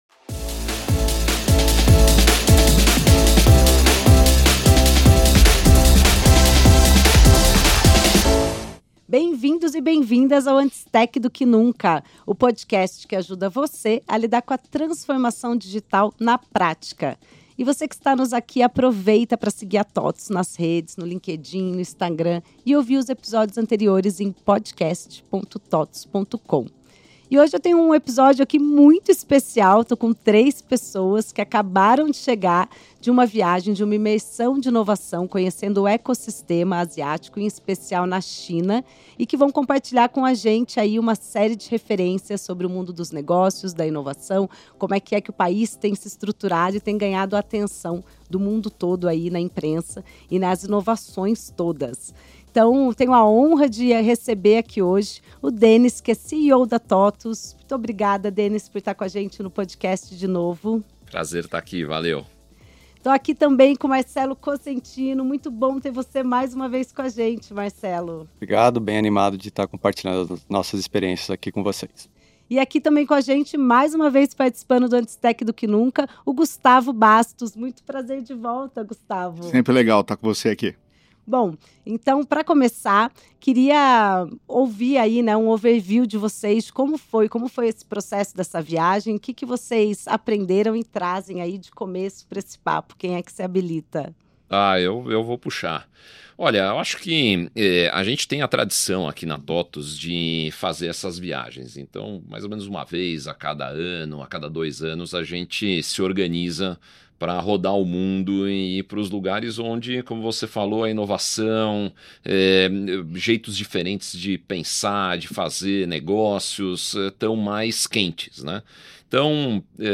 Tecnologia de ponta, soluções inovadoras e empresas que estão moldando o futuro dos negócios, tudo isso entra em pauta numa conversa prática, com casos reais e insights valiosos para quem quer entender como aplicar essas referências no contexto das empresas brasileiras.